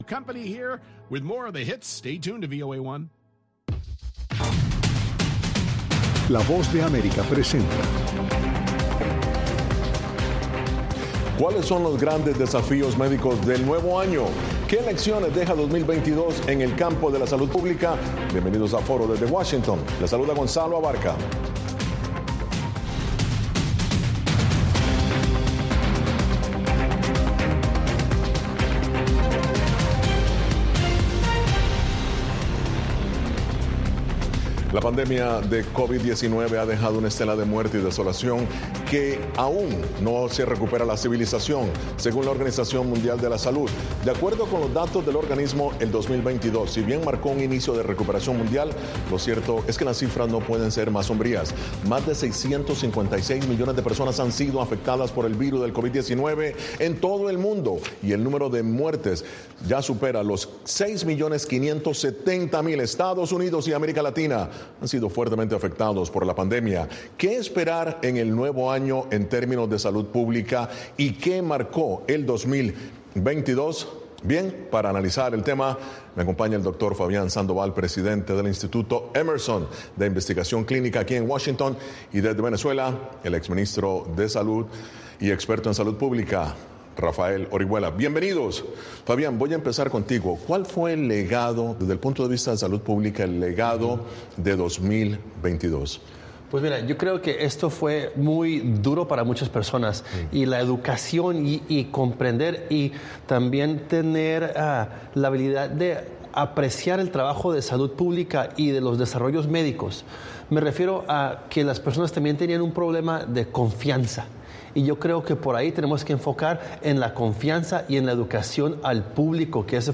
Los expertos comentan y ofrecen soluciones.